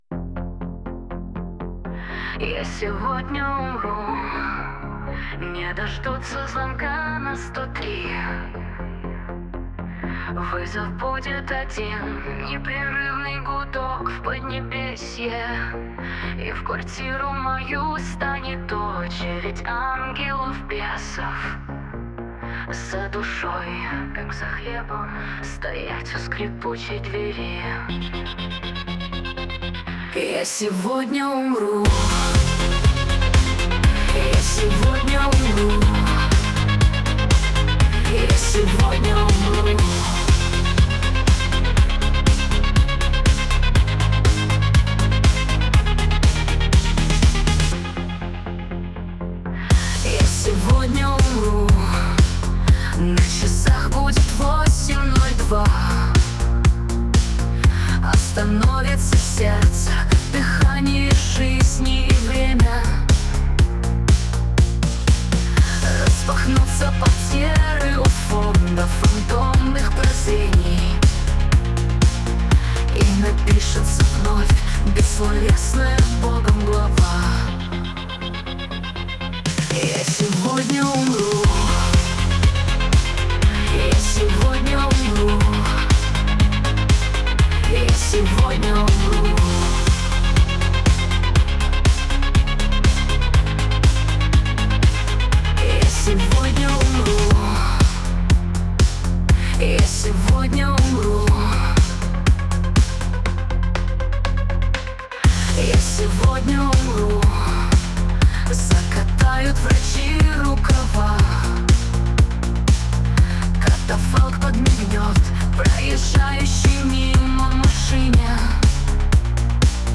Я сегодня умру -рок!!!!.mp3